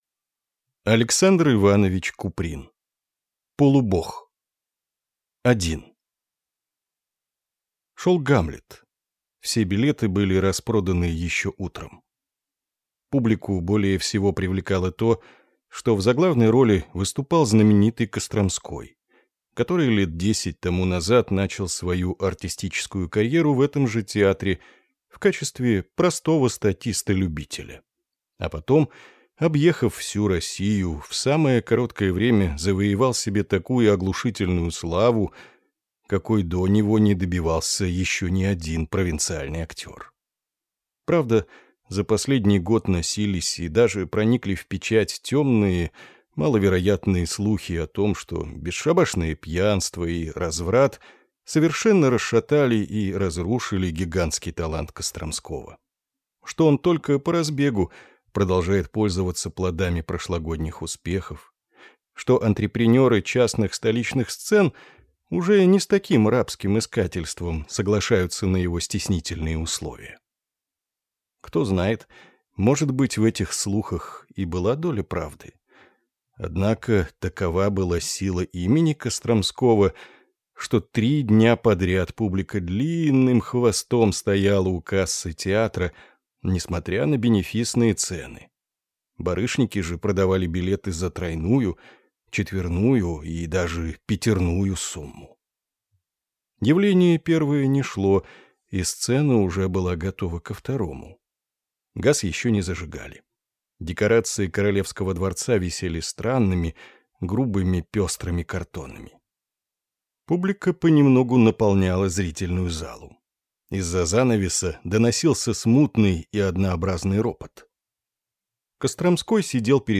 Аудиокнига Полубог | Библиотека аудиокниг